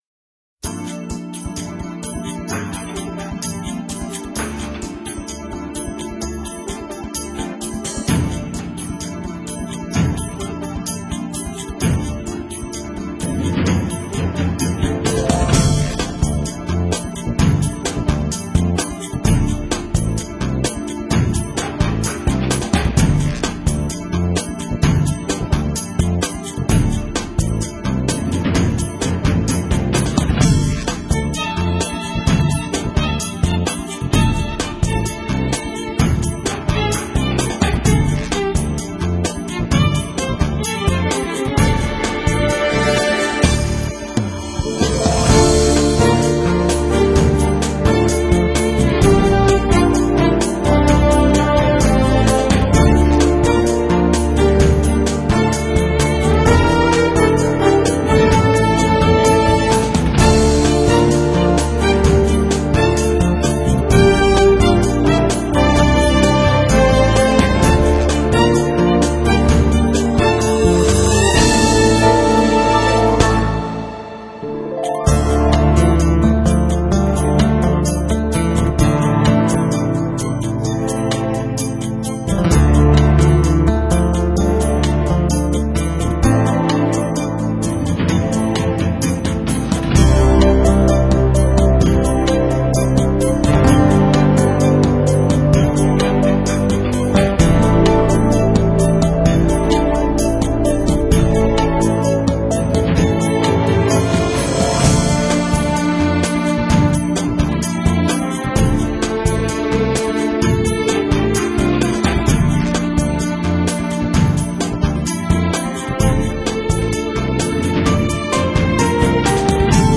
充分展现了电子键盘的那种神秘感，优雅感
音乐的层次感极其丰富，无论是钢琴的演奏，或者是沙器的点缀，都可以听来是那么的舒服，音乐旋律平缓中带有激情，柔美中含有荡漾